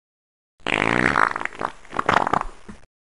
Fart Till Poop